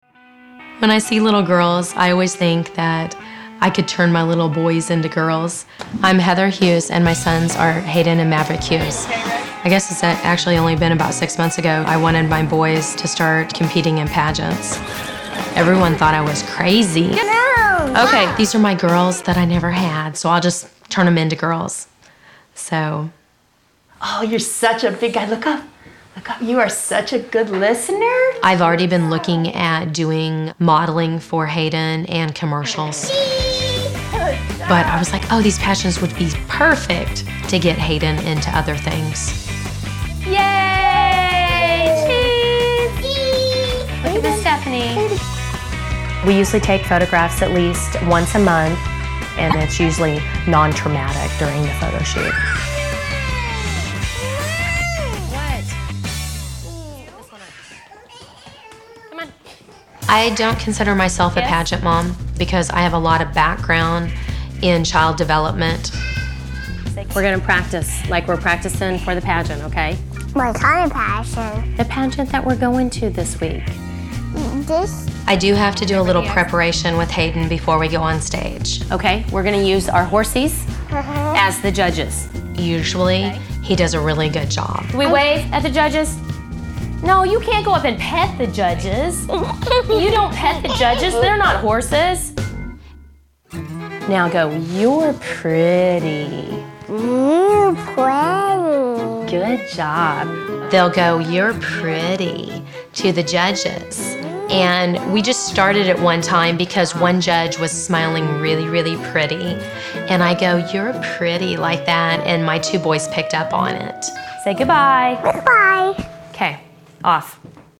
Tags: Toddlers and Tiaras Toddlers and Tiaras clips Toddlers and Tiaras Moms Toddlers and Tiaras interviews Toddlers and Tiaras sound clips